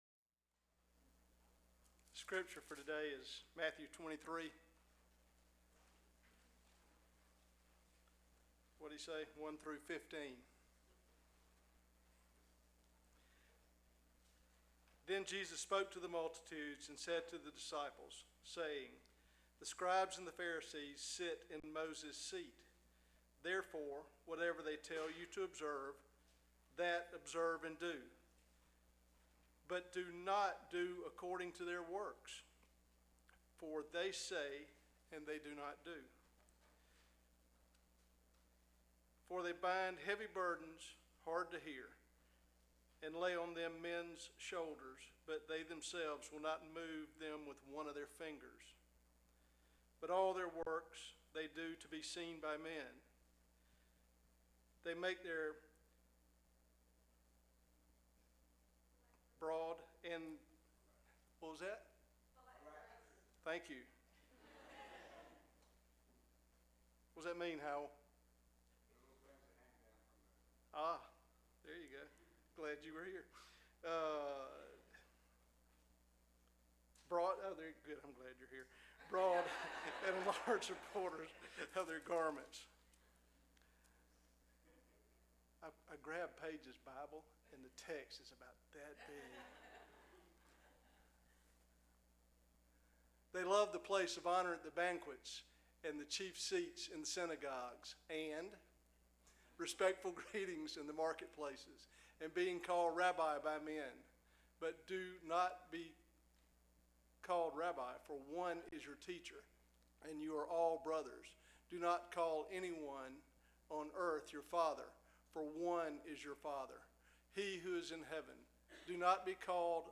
Sermons - OCILLA BAPTIST CHURCH